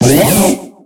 Cri de Limaspeed dans Pokémon X et Y.